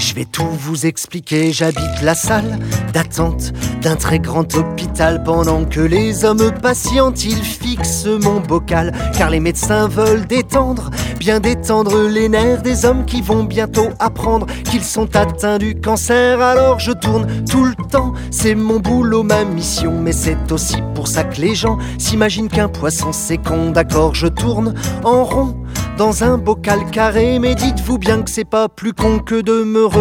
Chanson francophone - Chanson de variétés